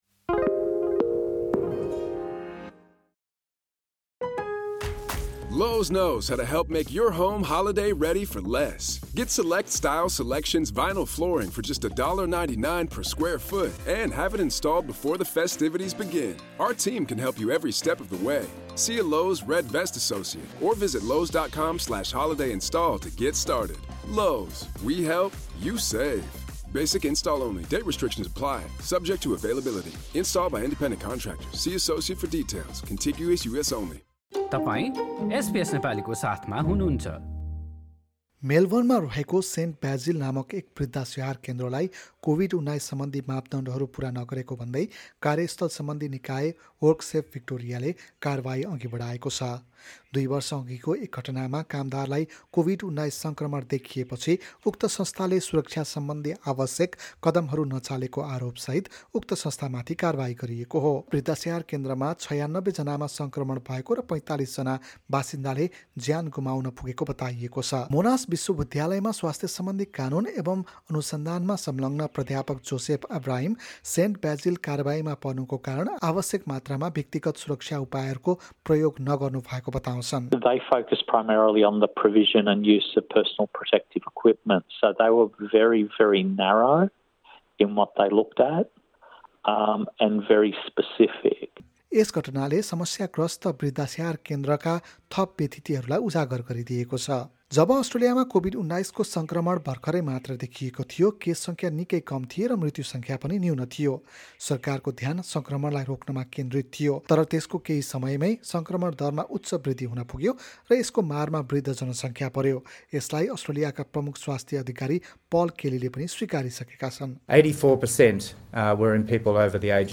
रिपोर्ट सुन्नुहोस्: कोभिड–१९ सङ्क्रमणलाई रोक्न आवश्यक कदम नचालेको भन्दै मेलबर्न स्थित एक एज्ड केयर माथि कारबाही हाम्रा थप अडियो प्रस्तुतिहरू पोडकास्टका रूपमा उपलब्ध छन्।